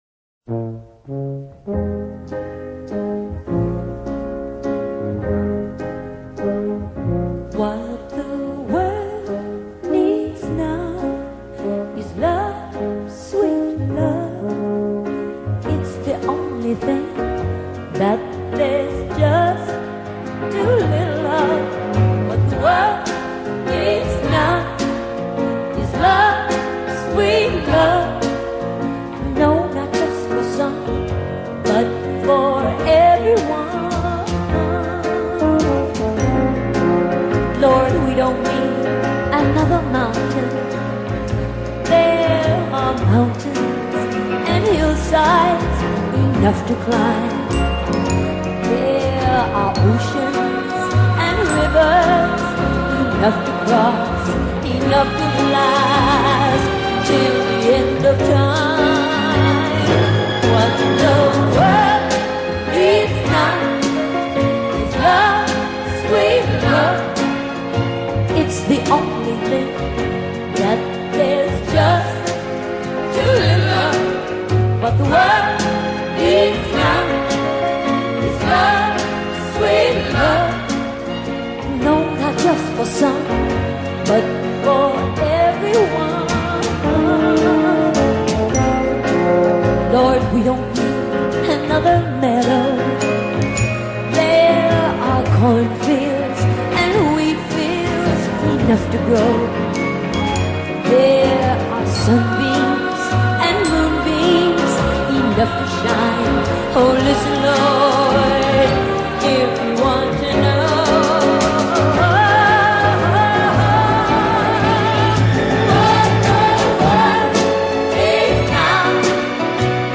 Join us for conversation with Deputy Mayor for Public Safety & Justice LINDSEY APPIAH! There is an increase in crime in every corner of the District.